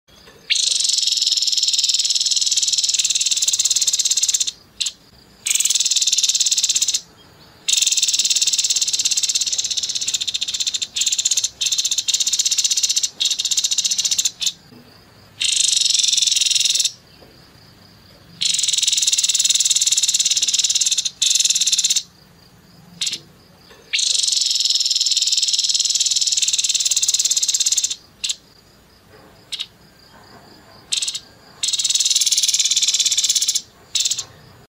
TEPUS KEPALA ABU NEMBAK PANJANG